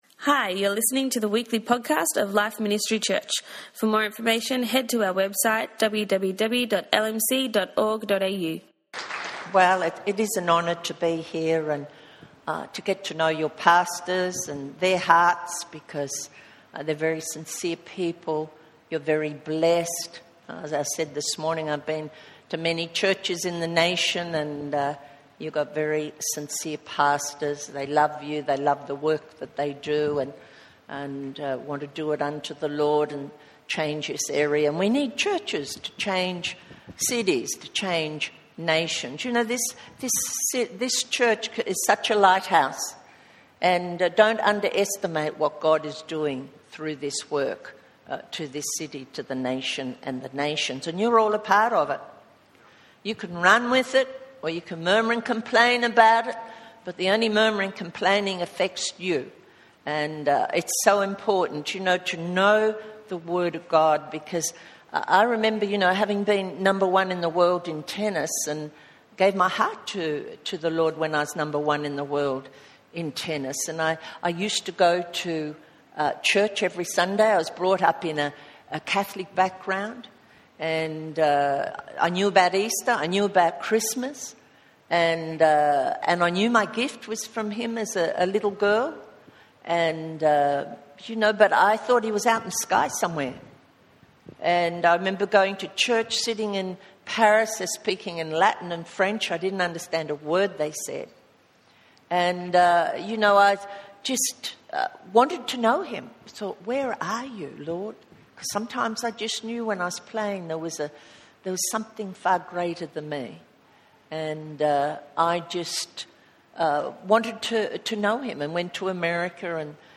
In this meeting centred around healing, Rev. Dr. Margaret Court shared her own testimony regarding healing, and prayed for us as a church.